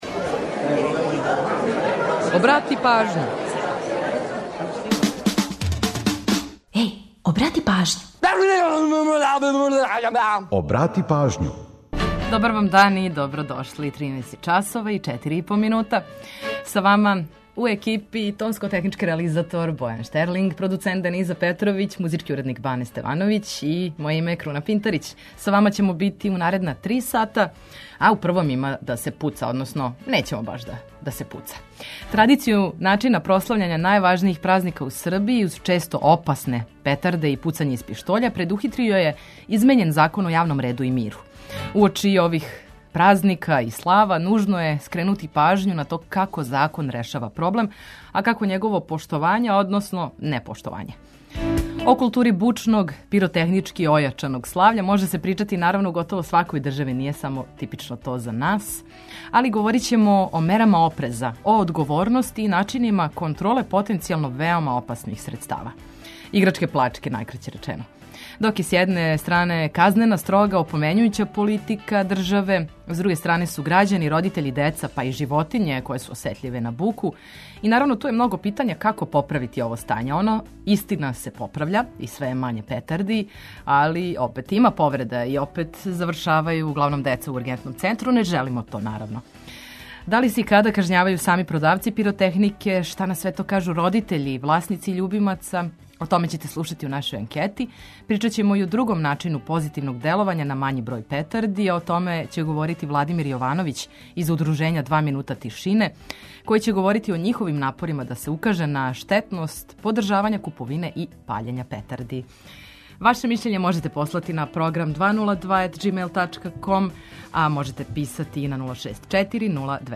О томе ћете слушати у нашој анкети, а причаћемо и о другом начину позитивног деловања на мањи број петарди.
Водимо вас и на концерт групе „Џинкс” у Дому омладине Београда, пажљиво ослушкујте!